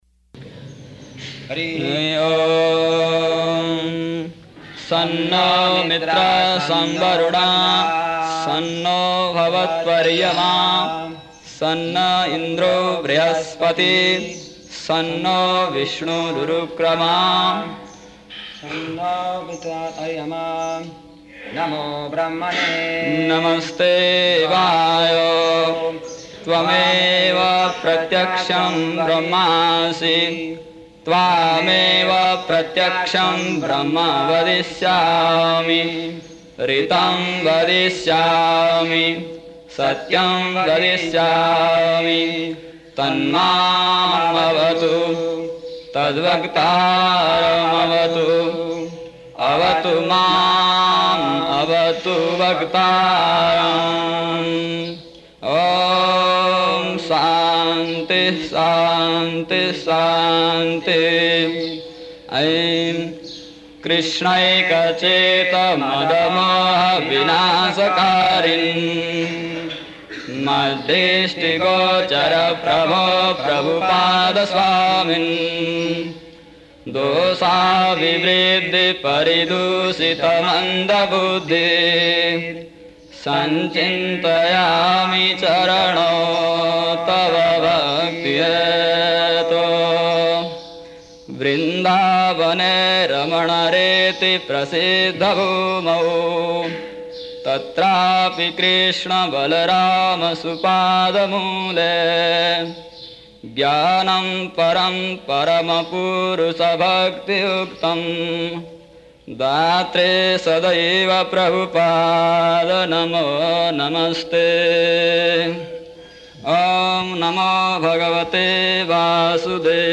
April 9th 1976 Location: Vṛndāvana Audio file
[chants verse; Prabhupāda and devotees respond]